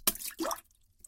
Звук падающего говна в унитаз с брызгами воды